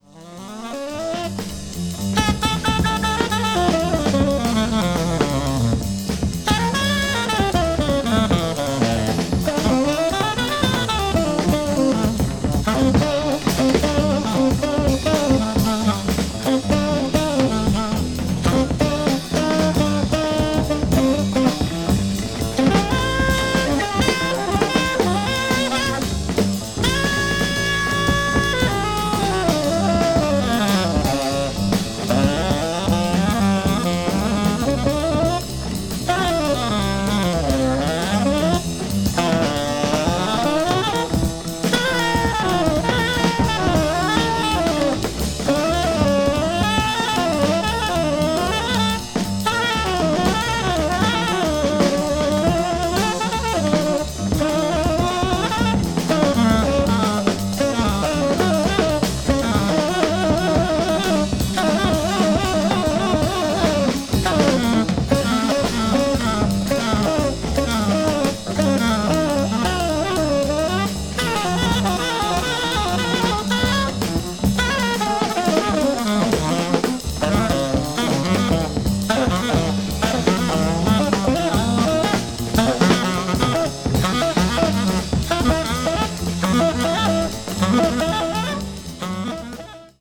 avant-jazz   free jazz   post bop   spiritual jazz